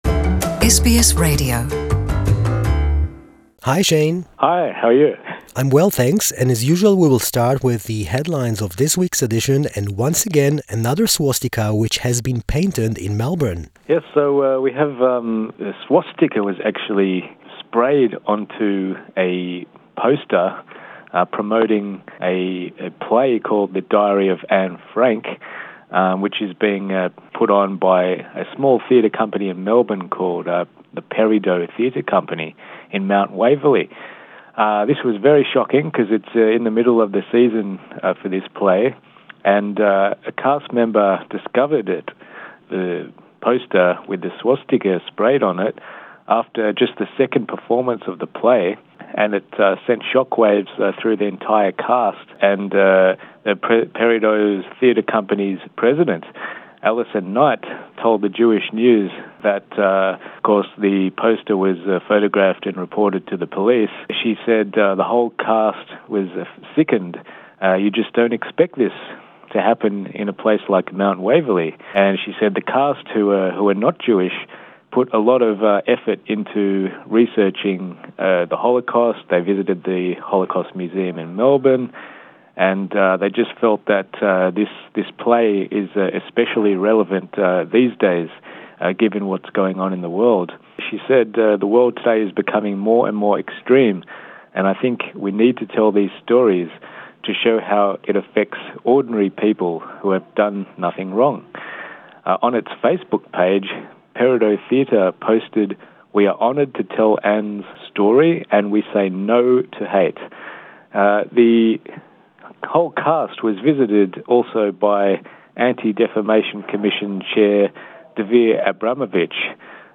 weekly report